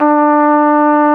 Index of /90_sSampleCDs/Roland LCDP12 Solo Brass/BRS_Flugelhorn/BRS_Flugelhorn 1